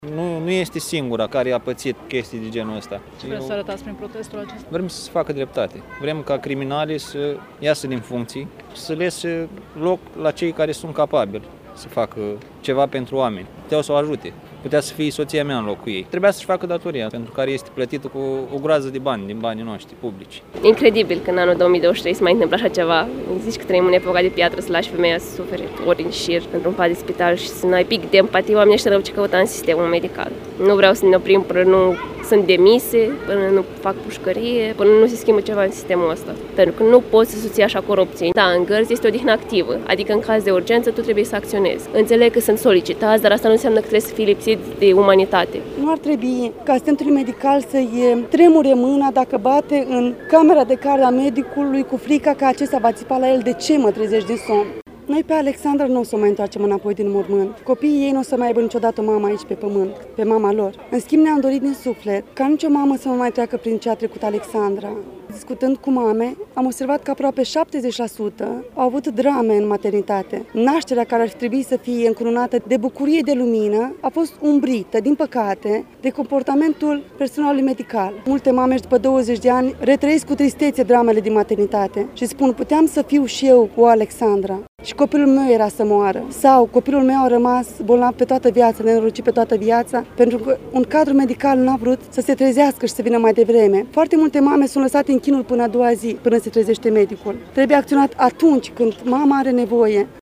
voxuri-protest-BT.mp3